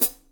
hihat-foot.wav